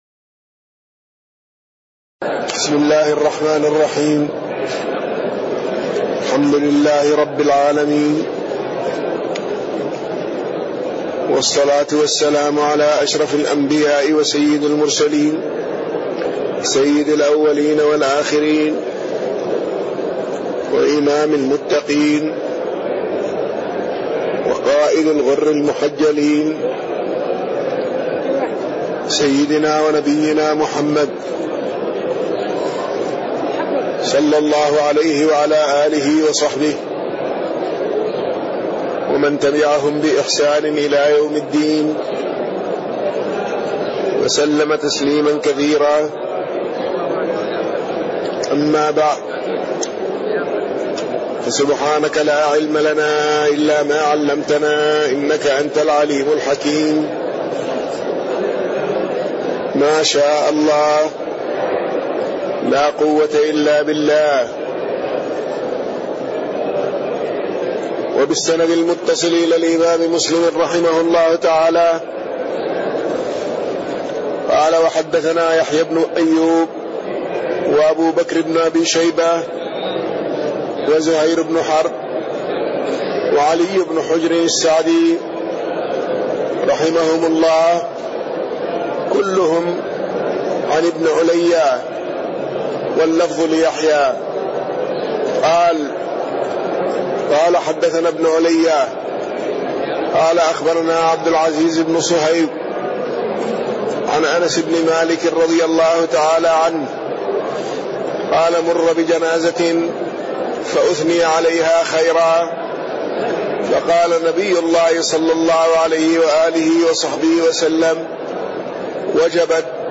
تاريخ النشر ٢٧ ربيع الأول ١٤٣٢ هـ المكان: المسجد النبوي الشيخ